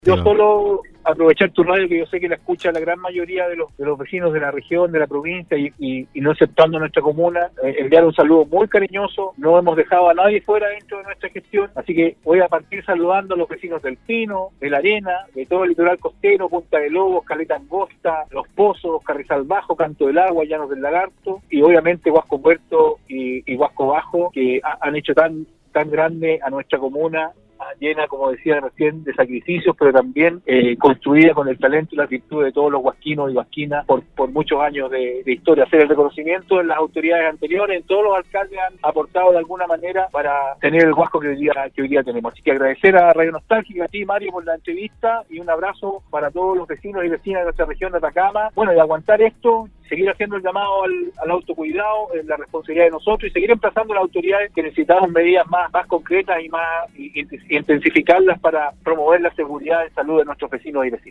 Este jueves la comuna de Huasco cumple 170 años, Nostálgica conversó con su alcalde Rodrigo Loyola quien destacó la trascendencia  de este importante hito llenó de historias y tradiciones, que han permitido el desarrollo de la comuna, que si bien es pequeña no está exenta de dificultades que con el pasar de los años han logrado ir superando.